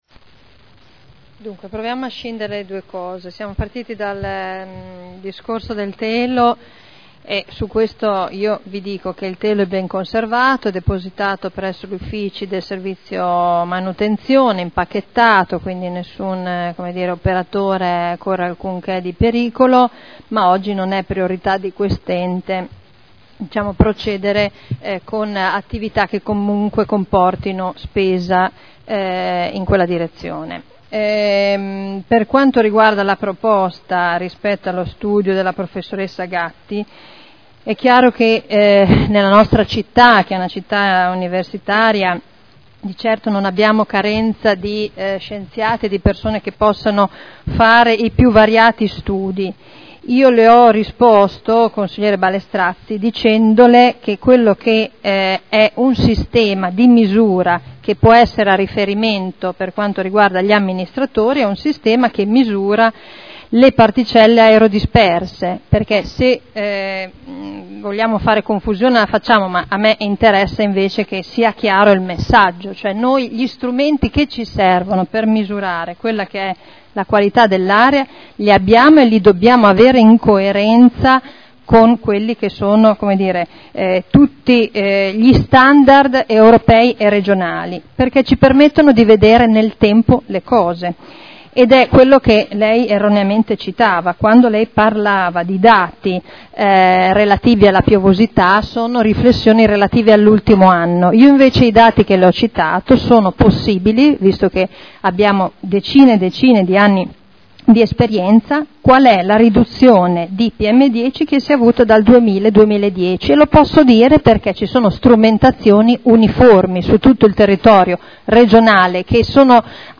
Seduta del 27 febbraio. Interrogazioni dei consiglieri Bellei e Ballestrazzi sul telo di Paladino Conclusioni